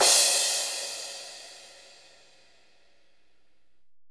THIK CRSH.wav